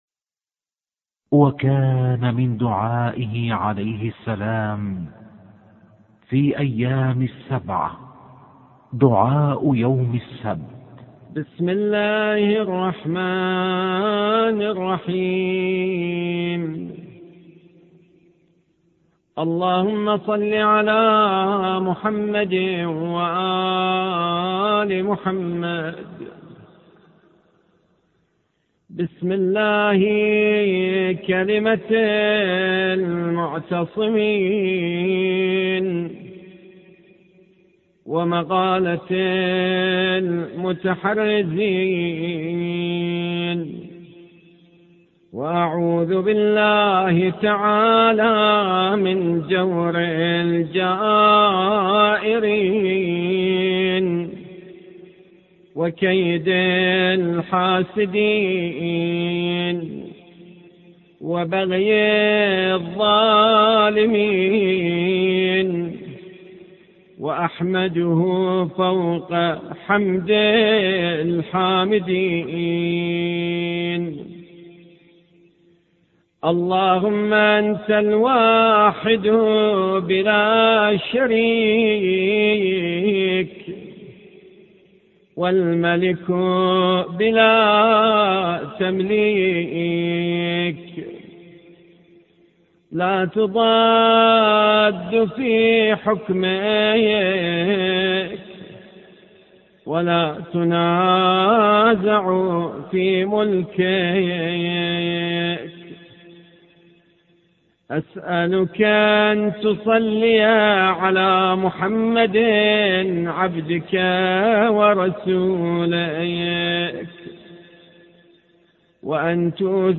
دعاء يوم السبت مكتوبة